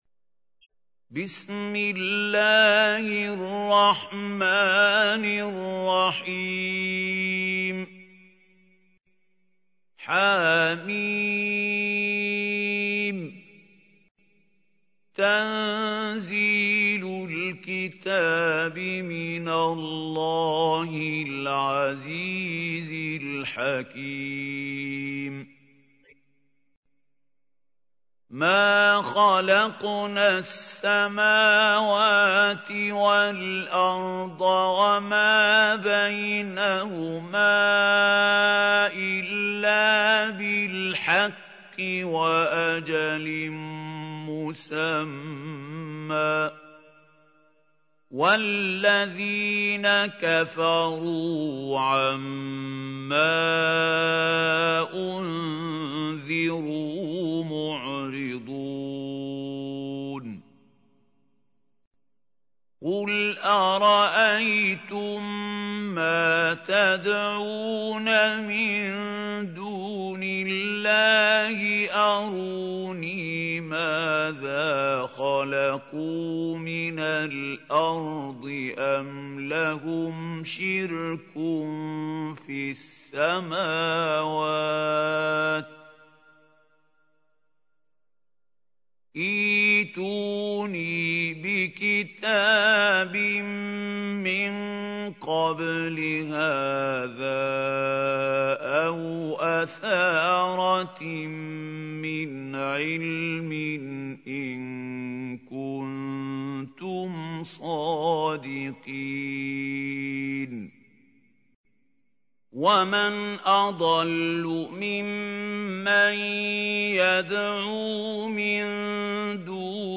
تحميل : 46. سورة الأحقاف / القارئ محمود خليل الحصري / القرآن الكريم / موقع يا حسين